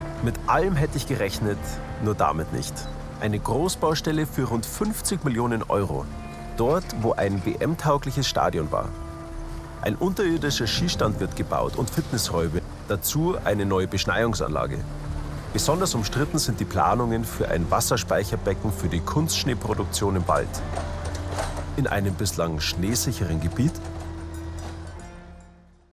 Felix Neureuther zur Baustelle in Antholz